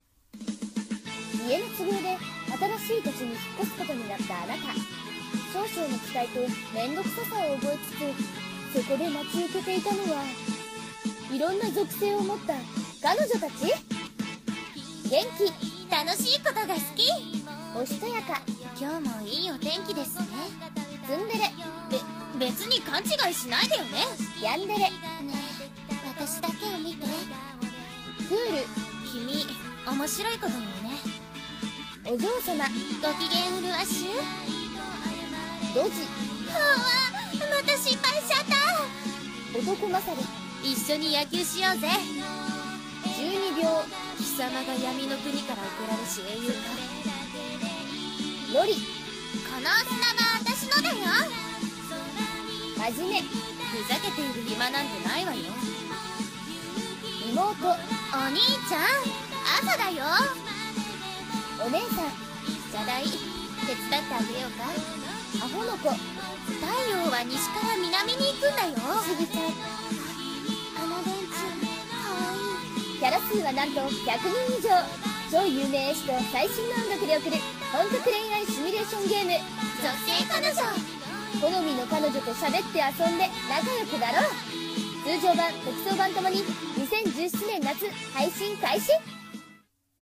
【ゲームCM風声劇】属性彼女っ♪